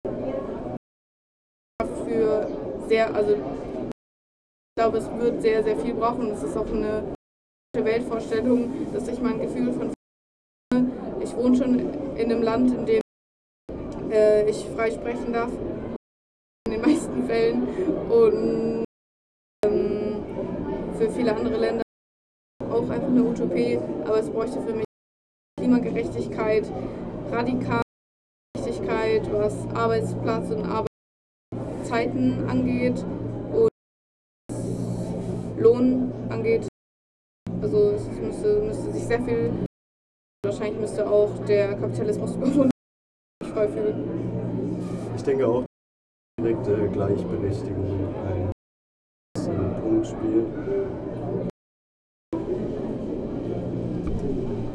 Was ist wichtig, damit sich ein Gefühl von Freiheit einstellt [Anmerkung der Redaktion: Dieses und die folgenden O-Töne haben eine schlechte Soundqualität]
Stendal 89/90 @ Stendal